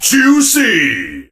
surge_kill_vo_04.ogg